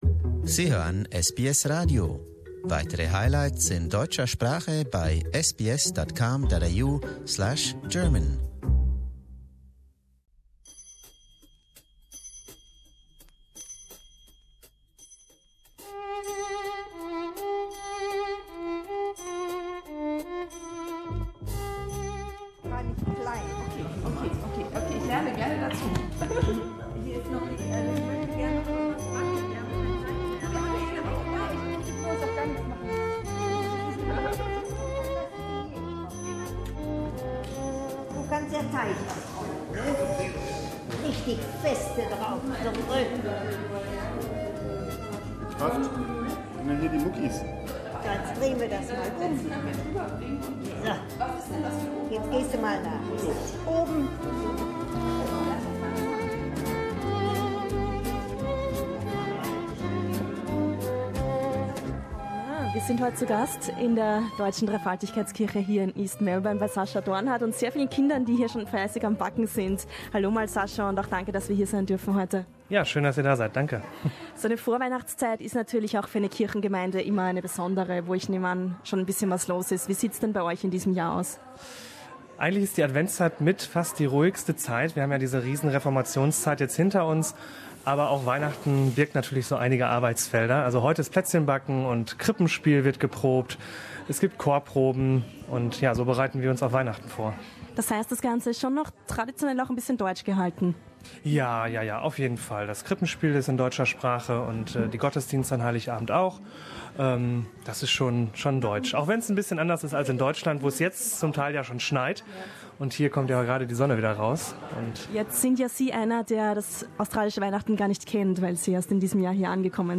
SBS German hat in der Vorweihnachtszeit die Deutsche Dreifaltigkeitskirche East Melbourne besucht und sich von den jüngsten Gemeindemitgliedern zeigen lassen, wie typisch deutsche Plätzchen gebacken werden.